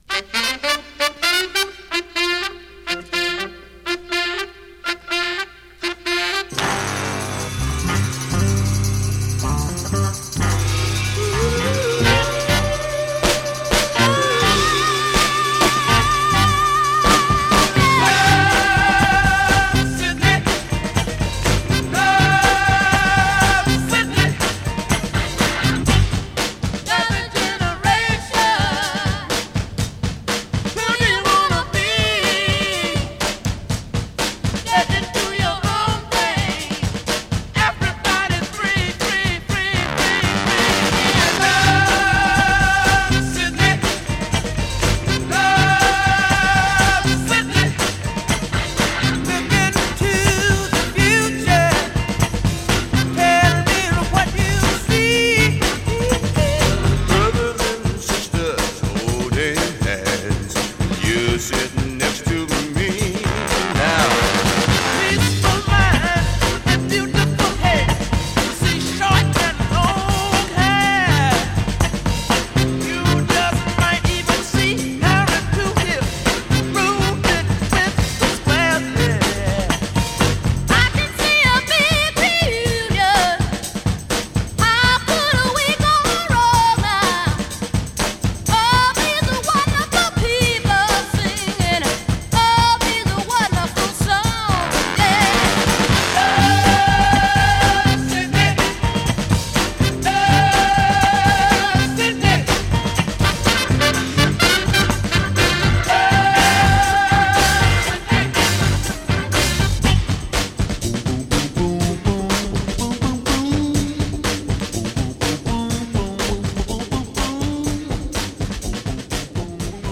SOUL